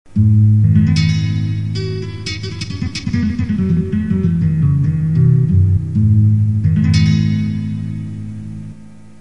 Spanish Guitar
A gently plucked guitar acoustic sound best suited to Fast Keyboard Runs designed to impress the ladies.
The sample you hear below was played (by me) and is maybe as fast as you can play the